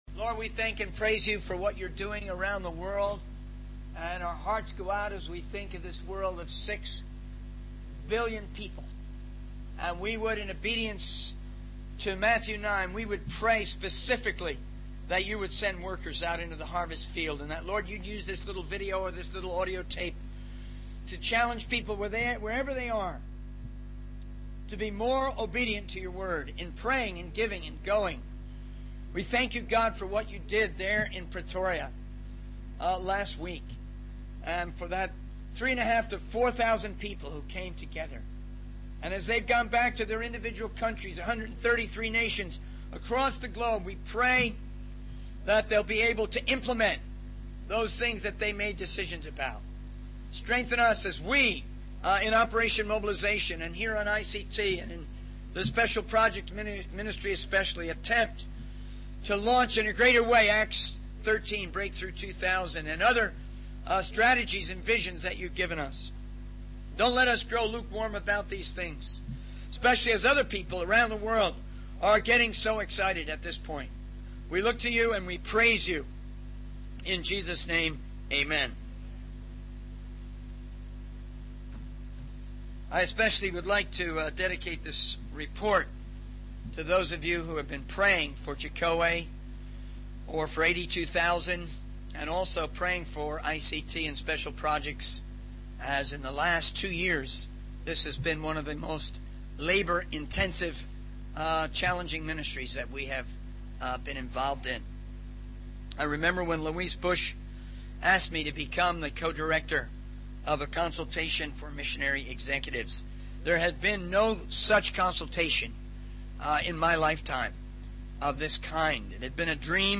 In this sermon, the speaker shares ten words that came to his heart while praying about his report. He emphasizes the importance of sharing the message of God in a way that provokes people to take action.